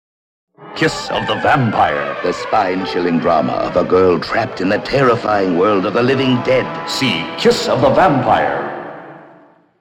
Radio Spots
The radio spots presented here are atmospheric, and capture the thrills in store for the theatergoer.